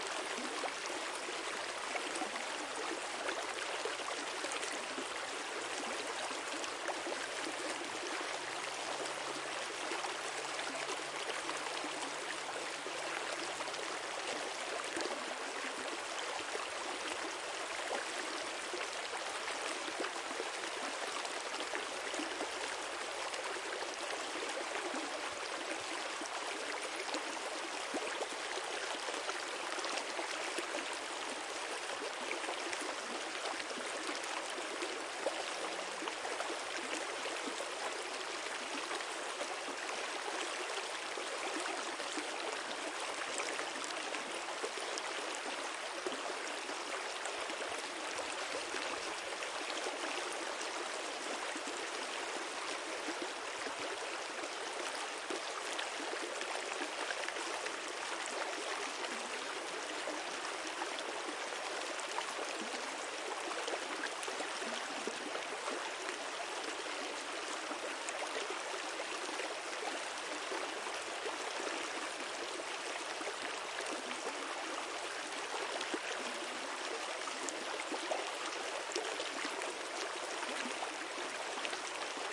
描述：录音：2003年4月10日上午10点左右，在法国Le Dognon（87）附近，在La Bobilance河畔。清晰而有力的声音技术：2台MKH20 + Jecklin Disk + TASCAM DAT录音机
Tag: 场记录 流量 河流 溪流